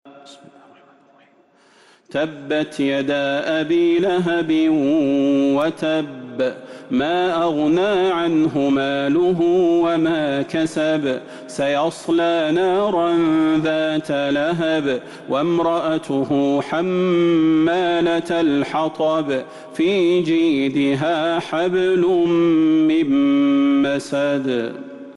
سورة المسد Surat Al-Masad من تراويح المسجد النبوي 1442هـ > مصحف تراويح الحرم النبوي عام 1442هـ > المصحف - تلاوات الحرمين